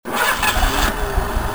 汽车引擎的声音 引擎音效
【简介】： 汽车引擎的声音、汽车发动的声音